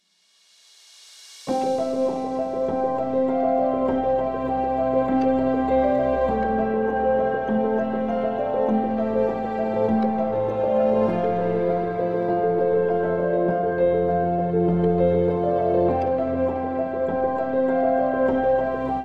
copyright free song